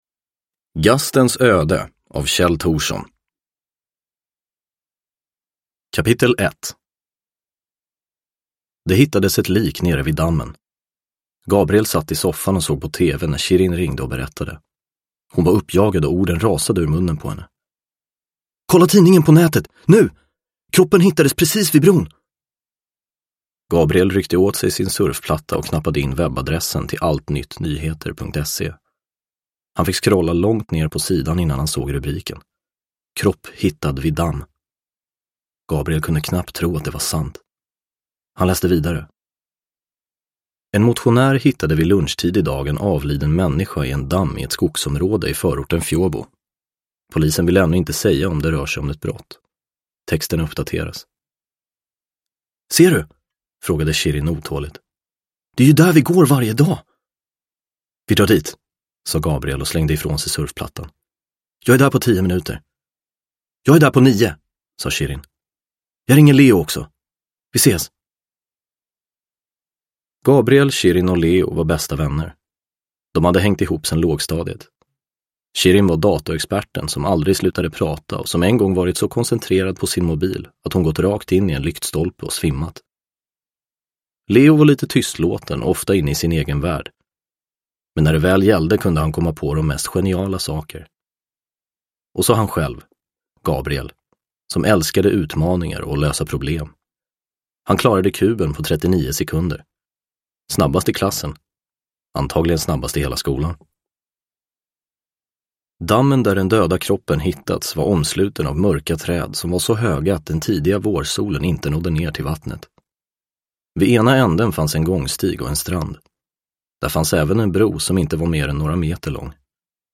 Gastens öde – Ljudbok – Laddas ner
Uppläsare: Anastasios Soulis